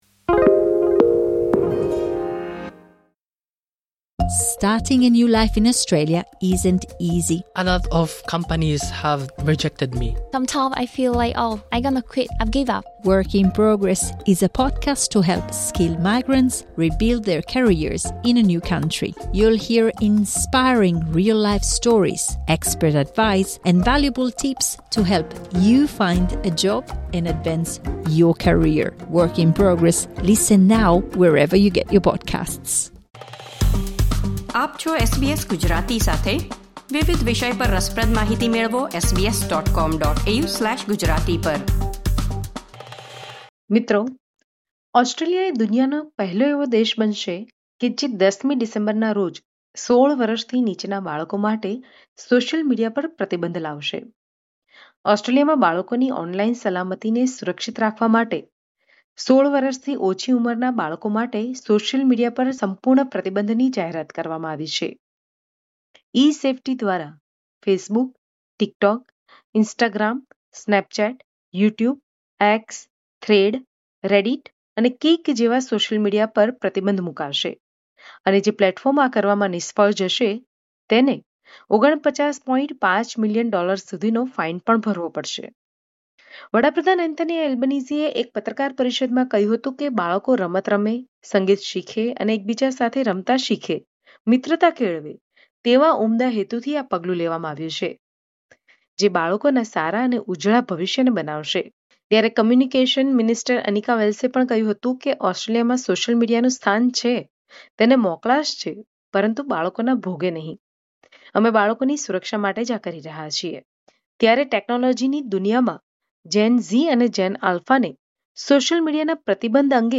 In order to protect children's online safety, a complete ban on social media for children under 16 has been announced in Australia. Then find out the opinions of the parents of the children in the report.